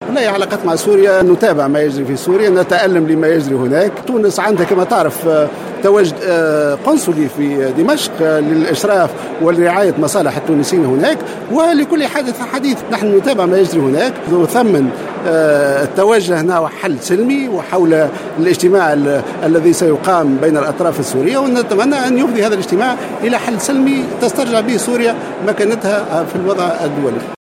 قال وزير الخارجية خميس الجهيناوي في تصريحات على هامش موكب انتظم مساء اليوم الاثنين بقصر قرطاج، بمناسبة إحياء الذكرى الستين لإحداث وزارة الشؤون الخارجية، إن تونس تتابع ما يحدث في سوريا و"نتألم لما يحدث هناك".